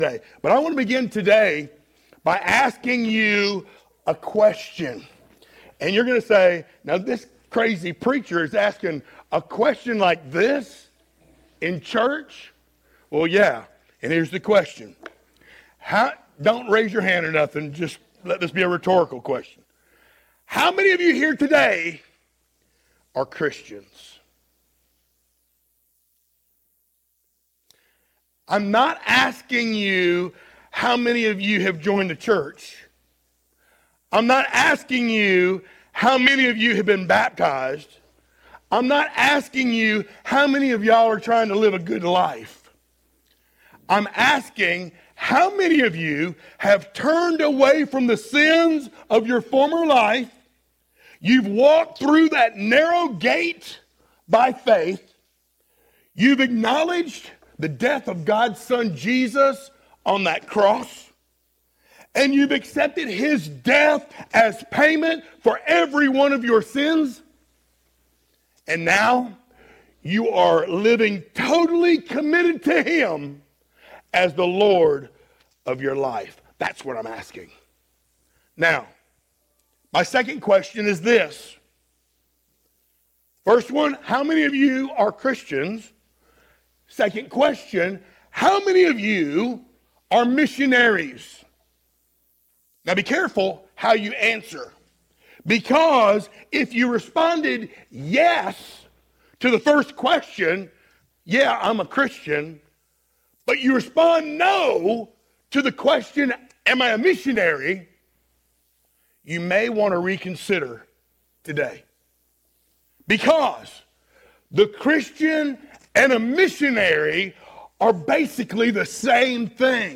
Series: sermons
Acts 1:4-11 Service Type: Sunday Morning Download Files Notes Topics